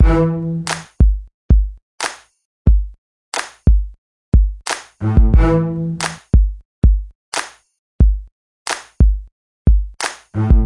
嘻哈节拍
描述：我在Reason中制作了一个简单的嘻哈节拍。有一个808踢腿和一个拍子，在开始和结束时有一个弦乐的打击。
Tag: 808 节拍 髋关节 嘻哈 串打